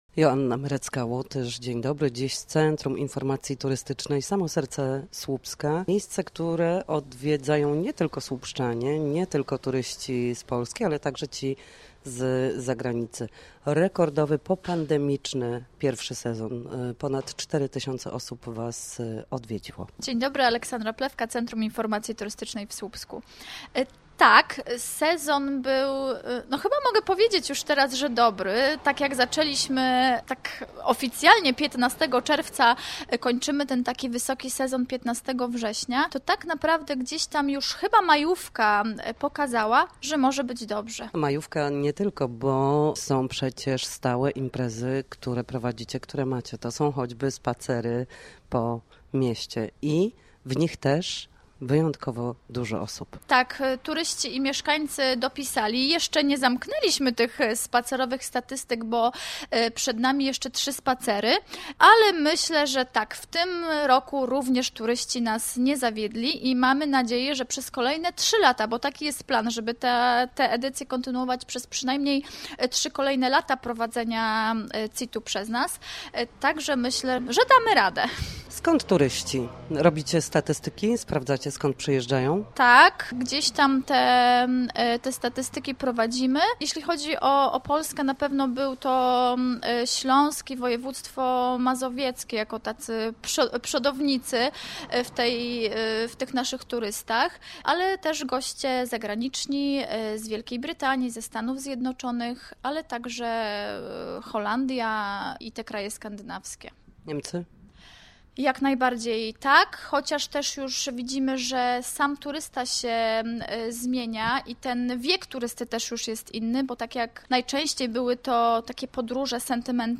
Posłuchaj rozmowy o turystyce w Słupsku: https